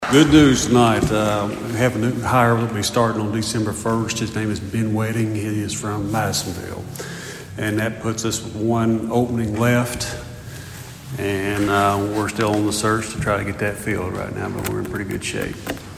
The Princeton City Council’s meeting Monday night was brief but packed with positive updates, as department heads shared news of new hires, ongoing projects, and community events.